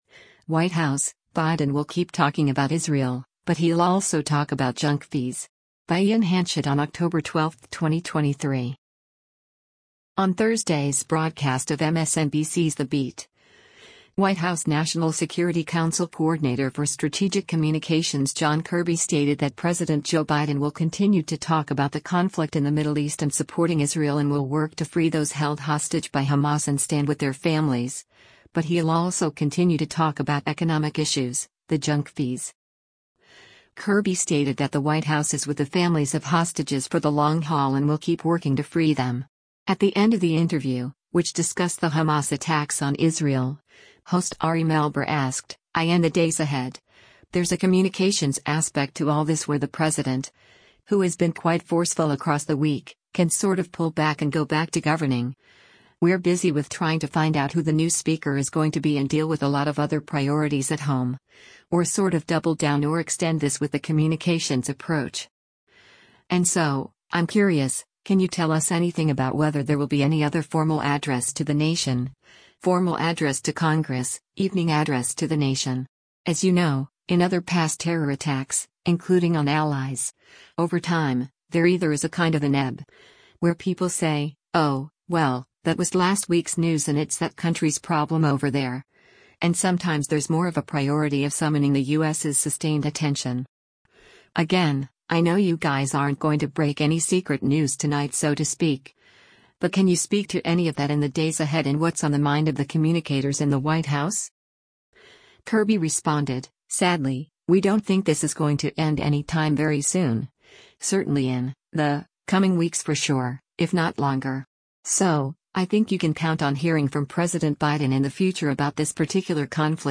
On Thursday’s broadcast of MSNBC’s “The Beat,” White House National Security Council Coordinator for Strategic Communications John Kirby stated that President Joe Biden will continue to talk about the conflict in the Middle East and supporting Israel and will work to free those held hostage by Hamas and stand with their families, but he’ll also continue to “talk about economic issues, the junk fees.”